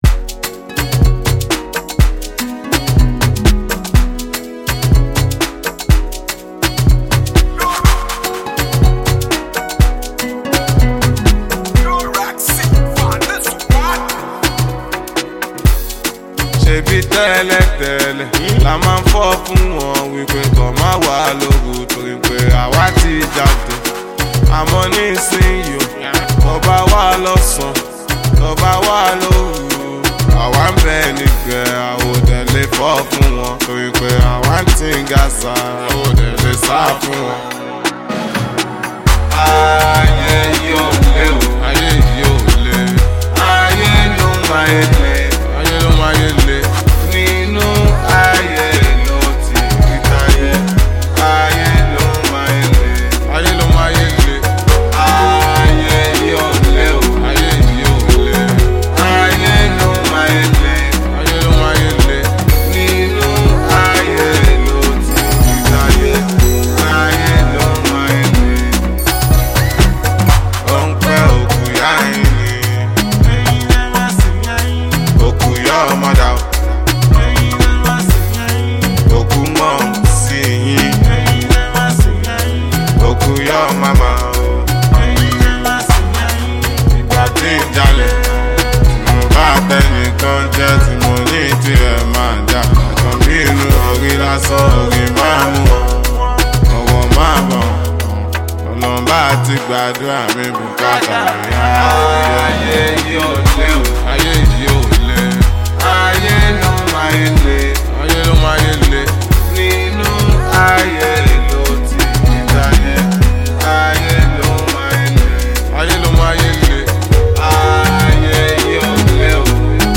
mid-tempo street pop tune with good instruments and vibe